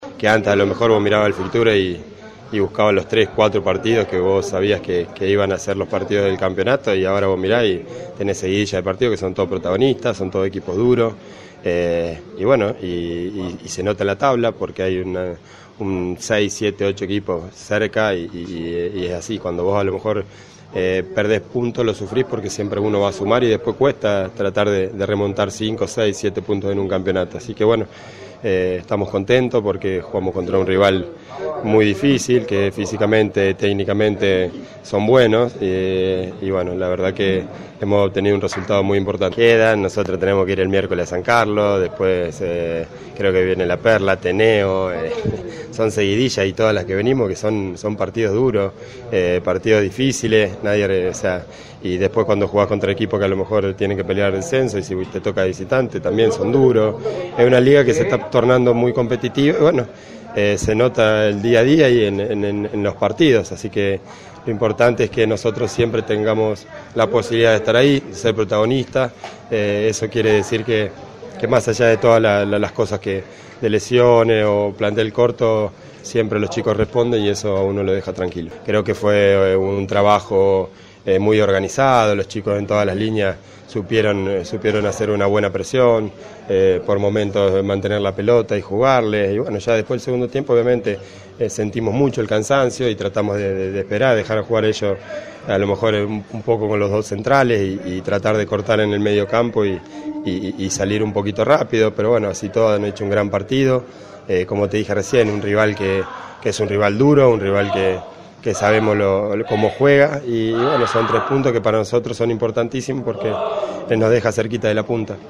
Escuchá a su DT: